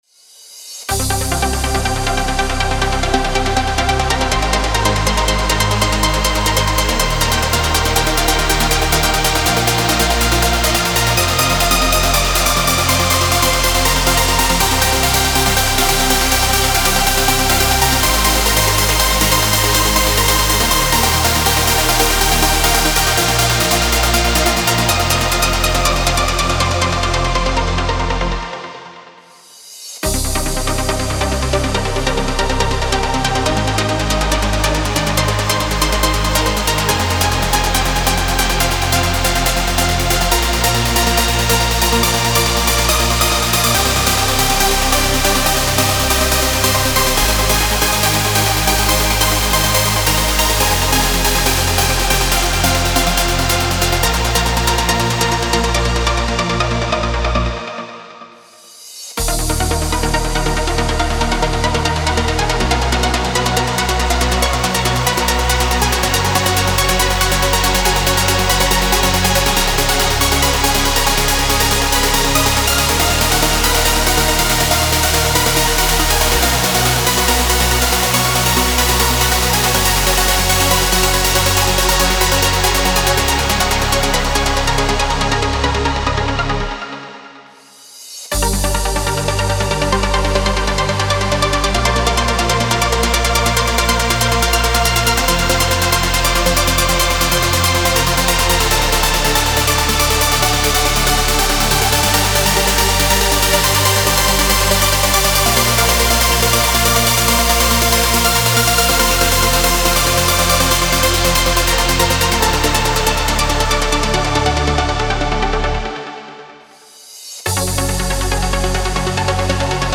Trance Uplifting Trance
15 x Main Melody
15 x Bass Line
(Preview demo is 140 BPM)
Style: Trance, Uplifting Trance